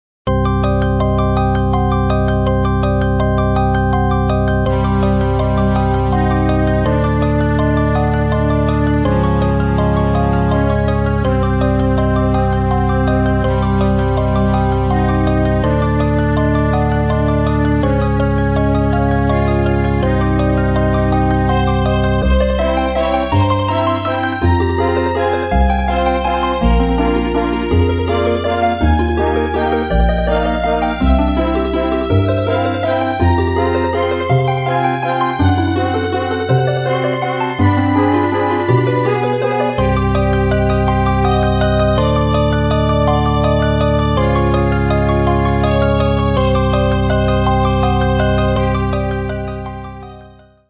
025 　重悲しい感じ〜ワルツ（Cm） 06/10/14